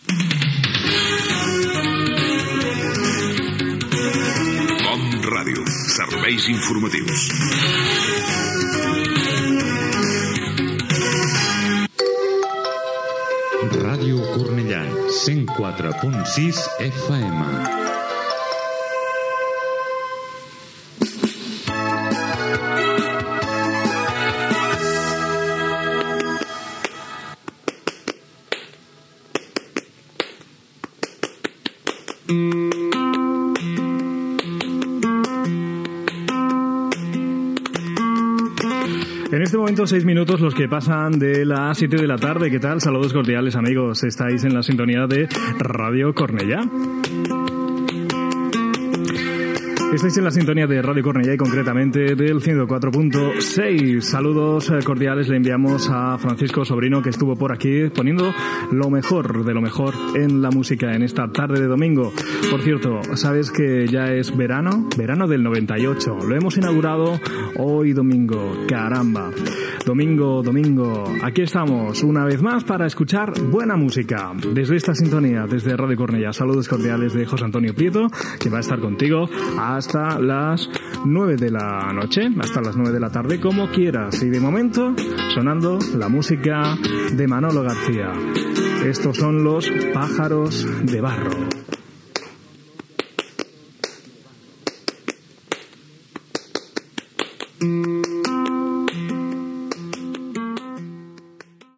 Careta de sortida dels Serveis Informatius de COM Ràdio, indicatiu de l'emissora, hora, tema musical